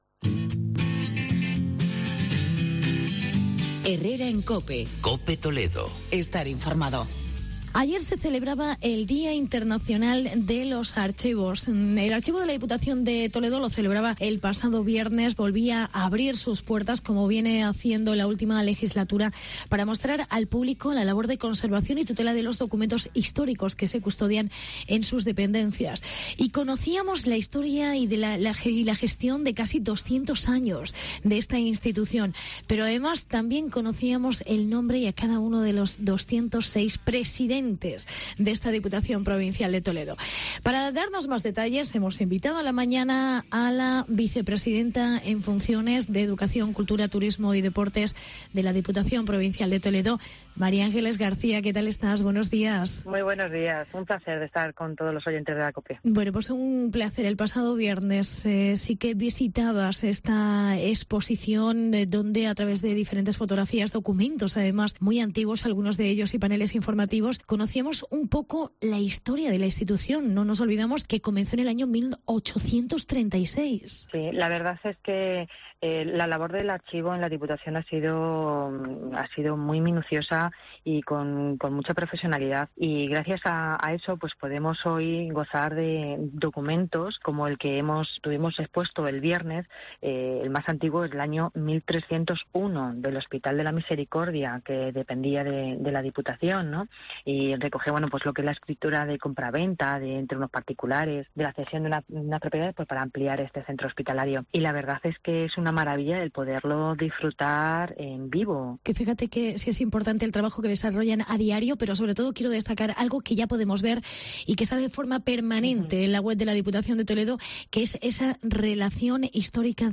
Entrevista con Mª Ángeles García. Vicepresidenta de Educación, Cultura y Turismo de la Diputación de Toledo